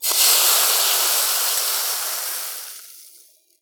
cooking_sizzle_burn_fry_10.wav